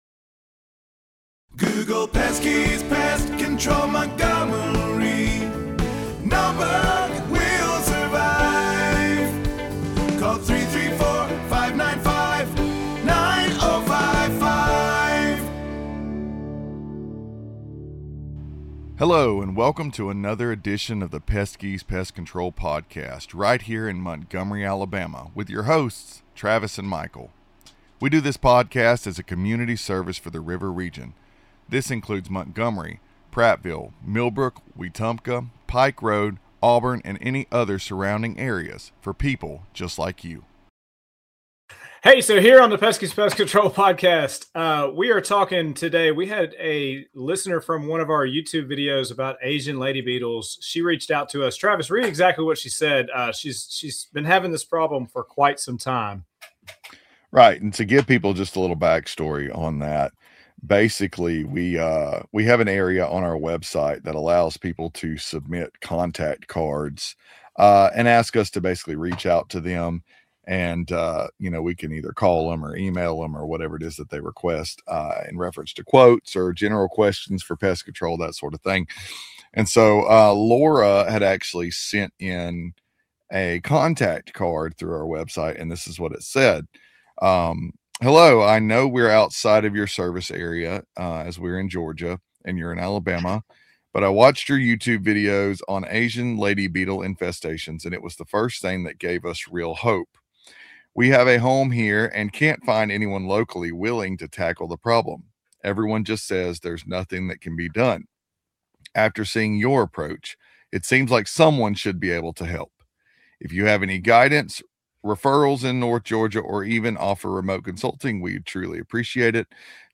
GA-Ladybeetle-Discussion-Podcast-MTG.mp3